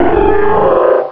pokeemerald / sound / direct_sound_samples / cries / lunatone.aif
-Replaced the Gen. 1 to 3 cries with BW2 rips.